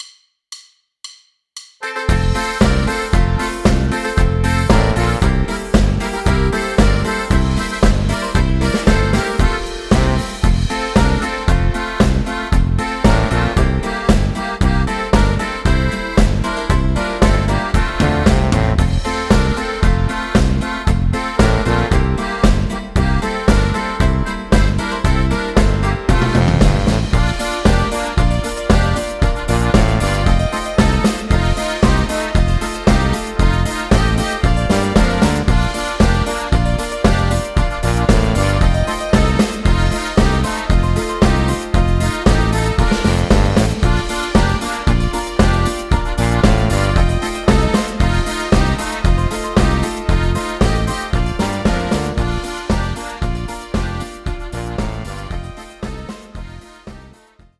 Playback, karaoké, instrumental